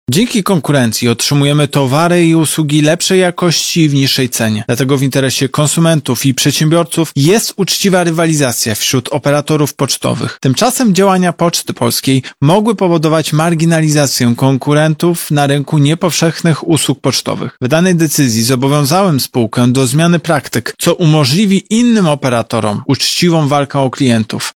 • mówi prezes UOKiK Tomasz Chróstny.